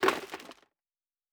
Plastic Foley Impact 5.wav